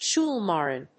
音節schóol・màrm 発音記号・読み方
/‐mὰːm(米国英語), ‐mὰɚm(英国英語)/